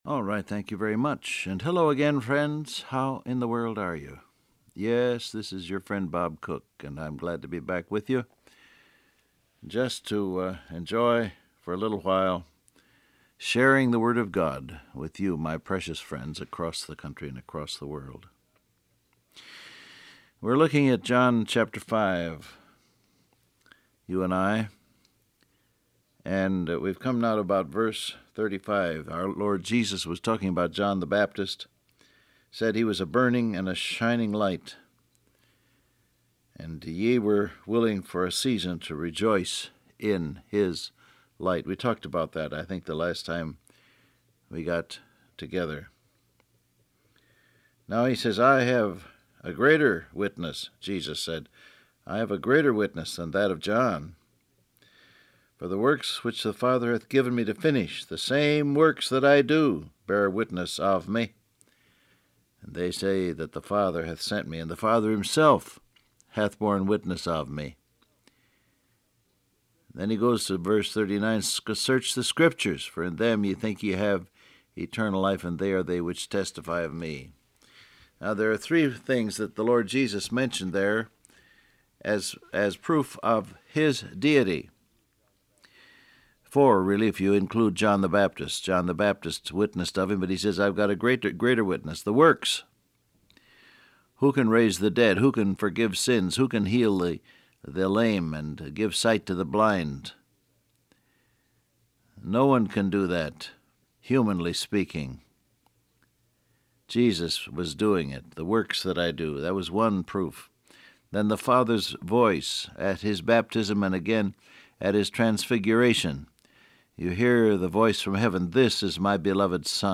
Download Audio Print Broadcast #6845 Scripture: John 5:36-39 Topics: Scriptures , Greater Witness , Honor From God Transcript Facebook Twitter WhatsApp Alright, thank you very much.